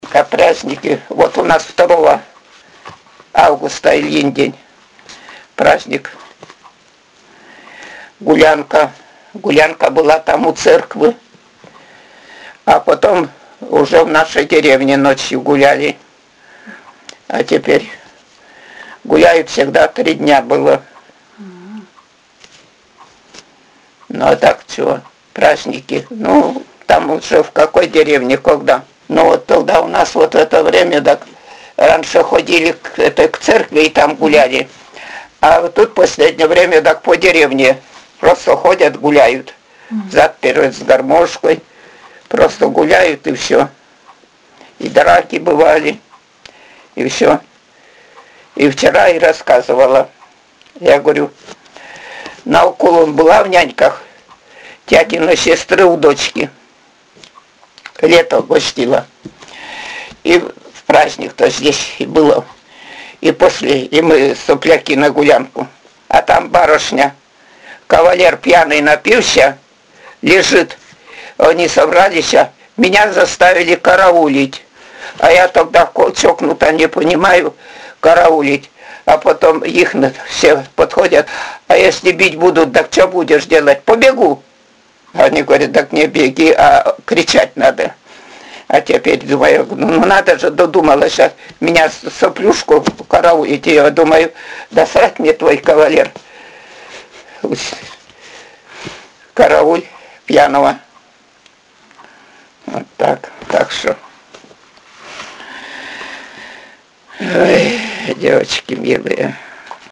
— Говор северной деревни
Пол информанта: Жен.
Аудио- или видеозапись беседы: